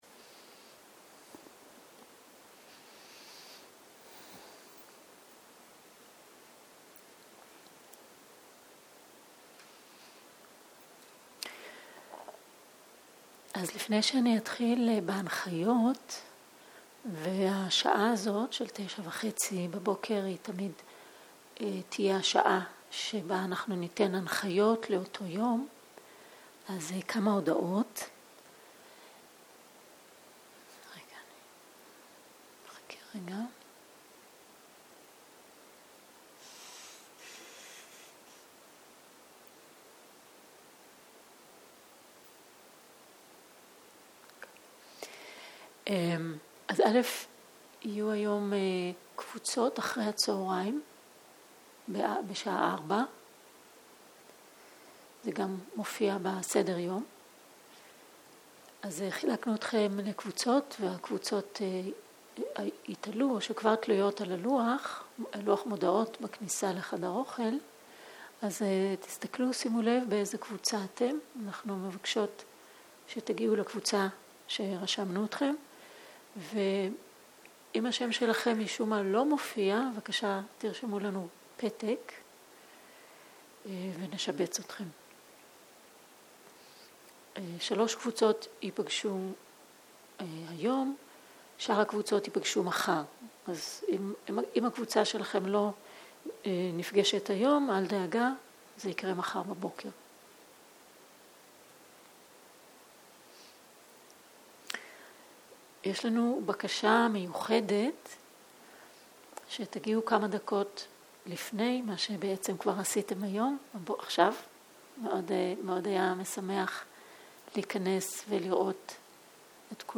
שיחת הנחיות למדיטציה